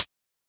edm-hihat-06.wav